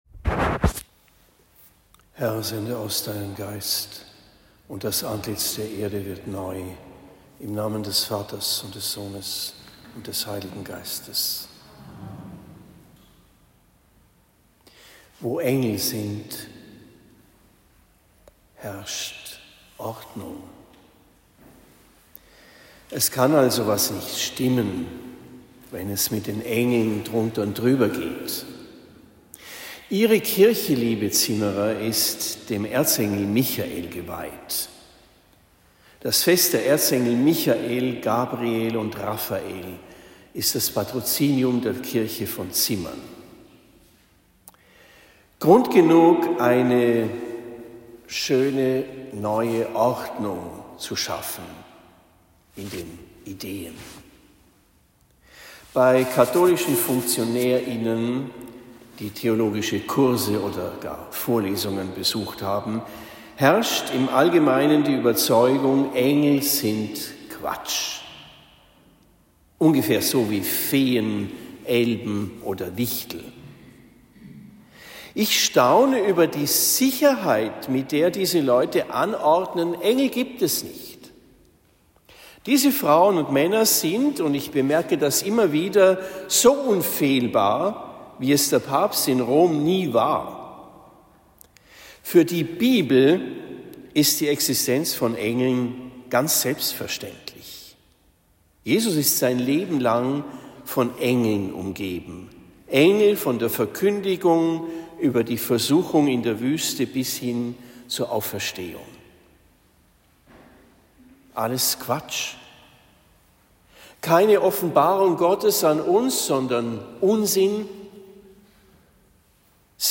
Fest der Heiligen Erzengel - Predigt zum Patrozinium
Fest der Heiligen Erzengel – Predigt zum Patrozinium Predigt in St.-Michael in Zimmern am 28. September 2025